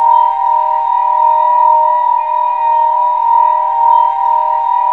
A#3 WHIST02L.wav